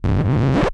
scratch17.wav